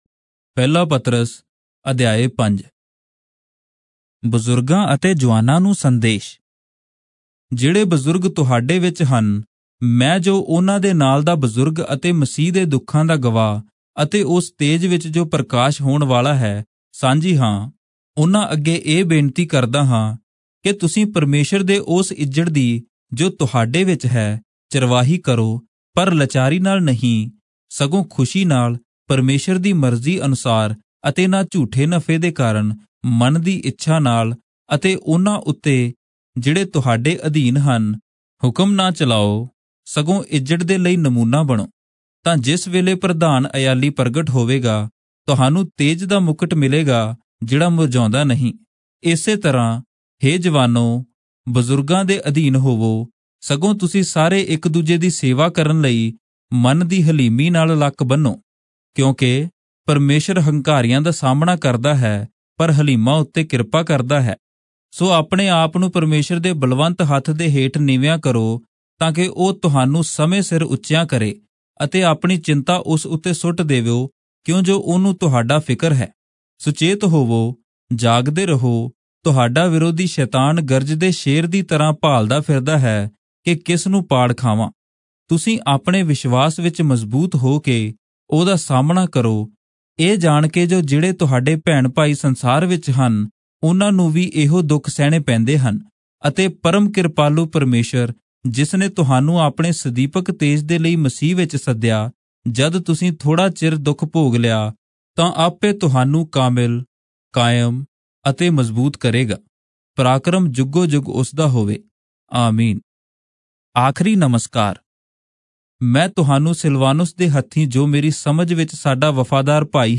Punjabi Audio Bible - 1-Peter 3 in Irvpa bible version